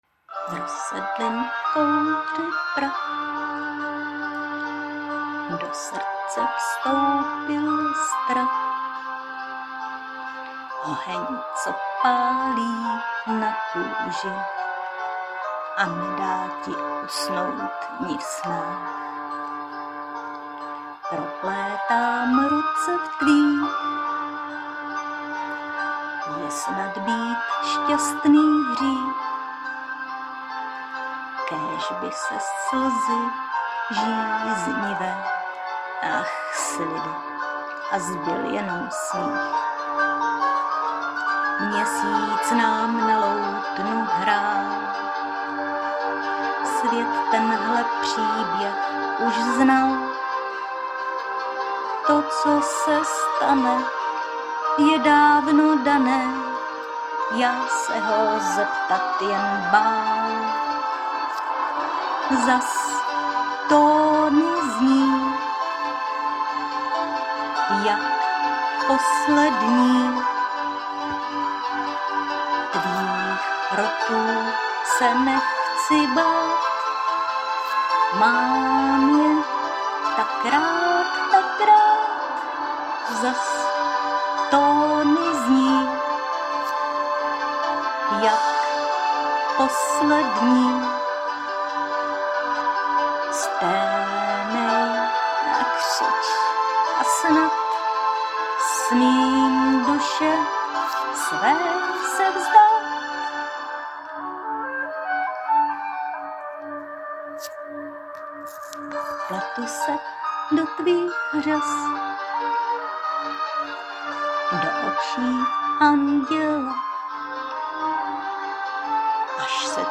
Mám je tak rád, tak rád ... hezký hlasový rozsah